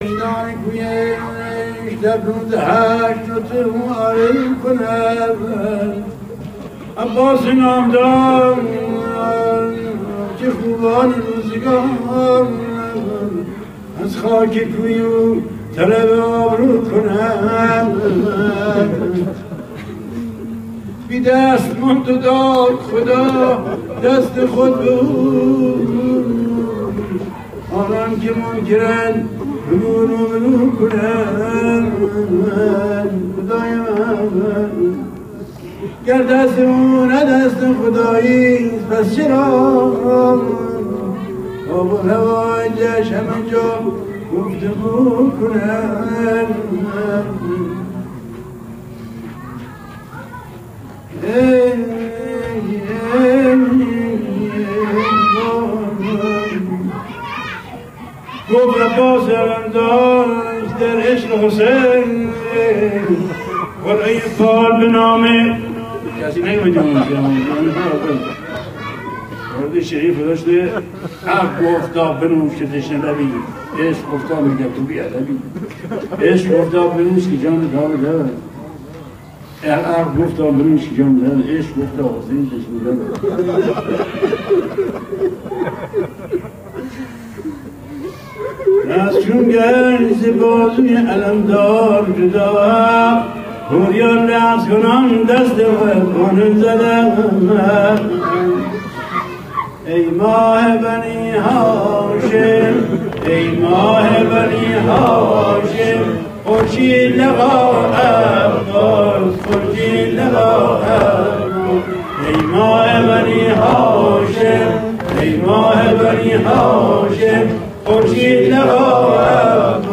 در دل شب‌های محرم، نغمه‌هایی بودند که با سوز دل مداحان قدیمی، راه دل را به کربلا باز می‌کردند.
آواز افشاری